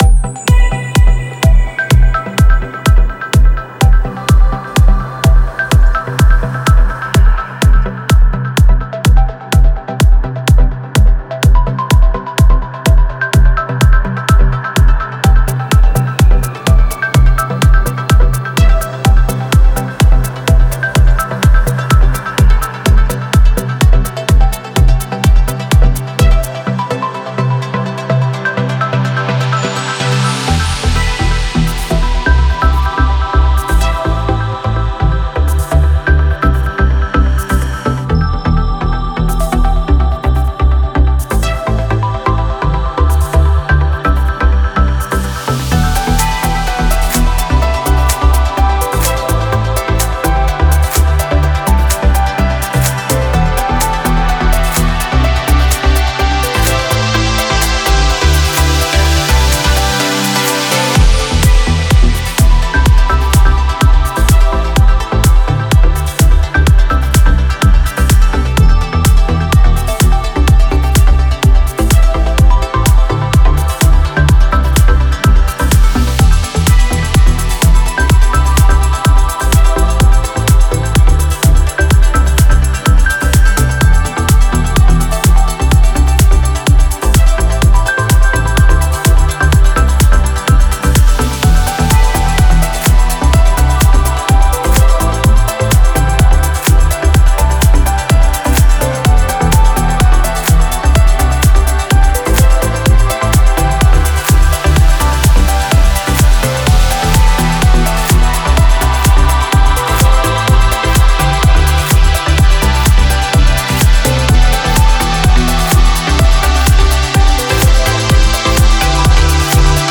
Стиль: Progressive House / Melodic Progressive